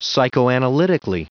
Prononciation du mot psychoanalytically en anglais (fichier audio)
Prononciation du mot : psychoanalytically